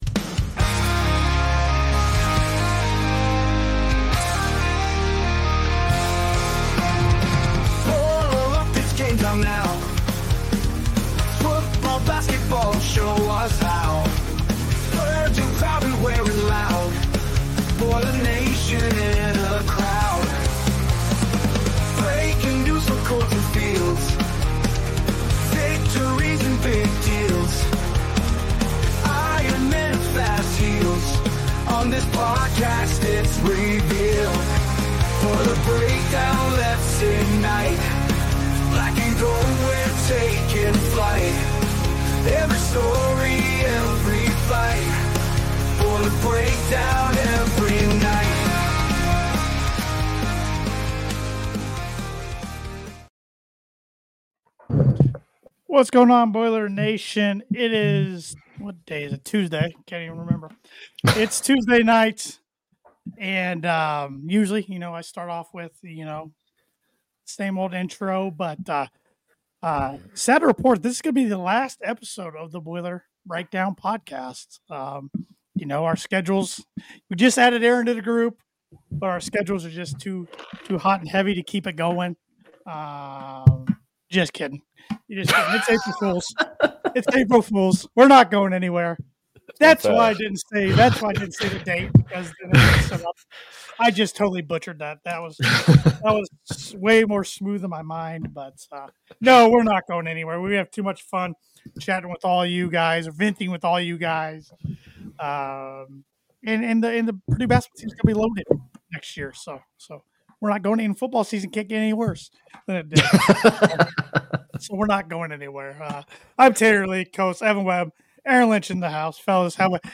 The Boiler Breakdown is a weekly podcast hosted by three lifelong Purdue fans who breakdown everything related to Purdue Football and Men's Basketball.